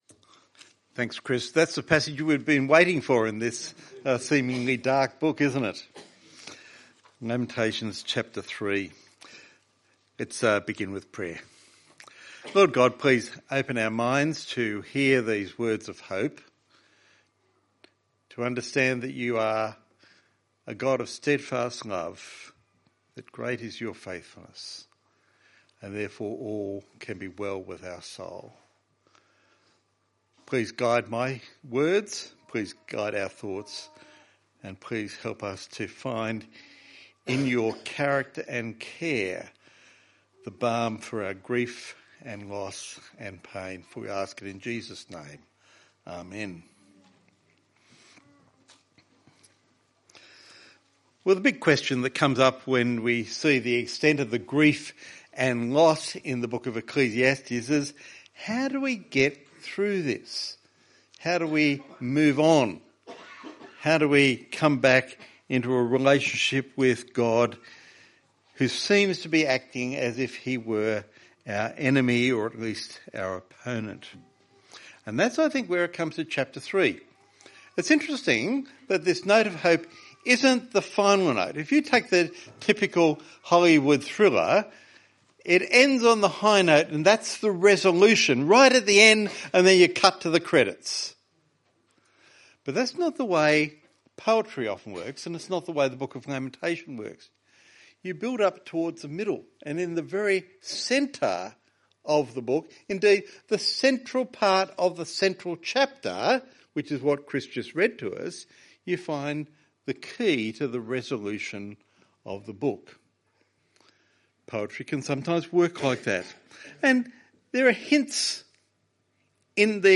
Recorded at church camp February 2025